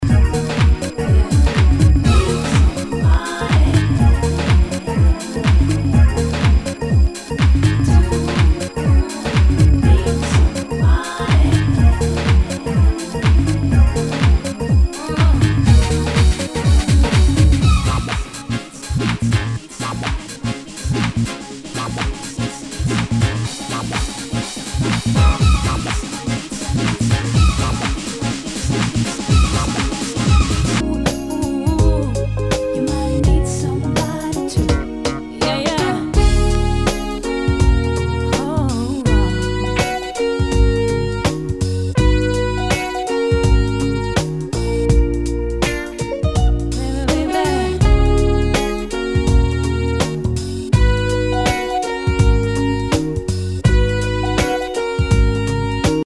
HOUSE/TECHNO/ELECTRO
ナイス！ディープ・ハウス / ダウンテンポ！！